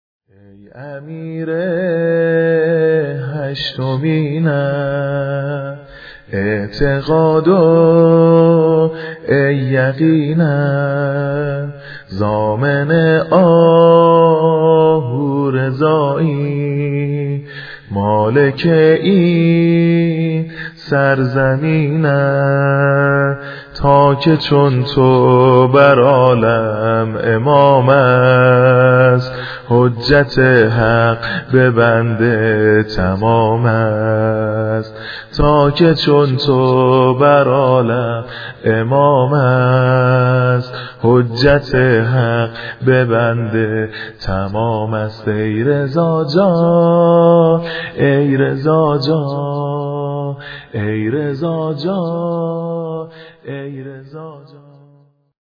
واحد ، نوحه